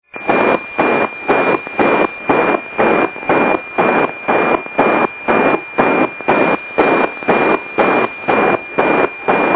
These are Amplitude Modulated 2.4khz carrier on an FM signal with around 38khz deviation.
When I started - this is all I could achieve: (Click the Image to hear what APT Transmissions sound like!)
That was achieved only with a dipole and trying to chase the satellite as it moved across the sky. While it wasn't really successful, I heard the first "pinging" sounds from the satellite and I saw some sort of imagery that looked like Earth.